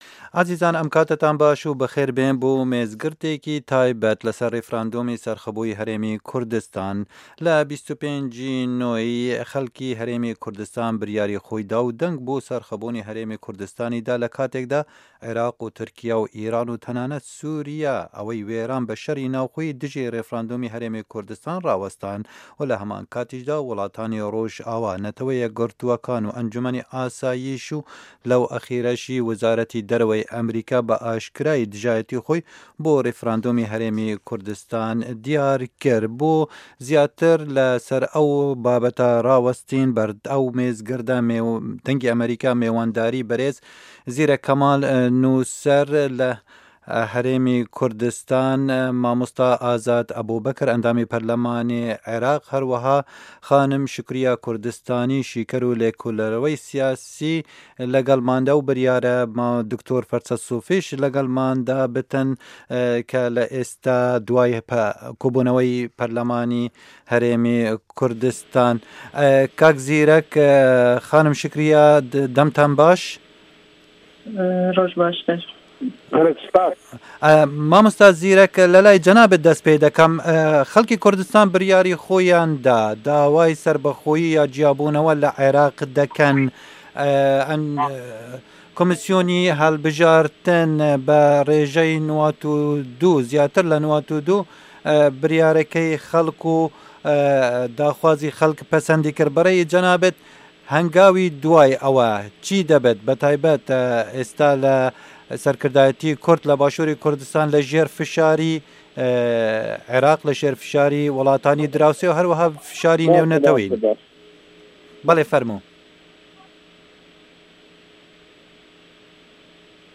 مێزگرد: ڕێفراندۆمی هه‌ریمی کوردستان